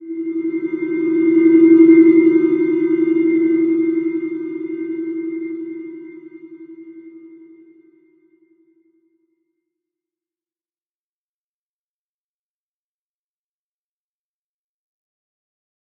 Slow-Distant-Chime-E4-mf.wav